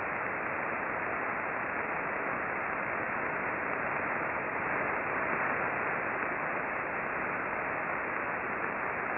RFI with a popping sound is apparent in the recordings.
The first L-burst was received at 1034 on the Red channel (19.897 MHz).
The burst is weak but clear.